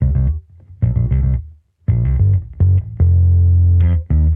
Index of /musicradar/sampled-funk-soul-samples/110bpm/Bass
SSF_PBassProc2_110C.wav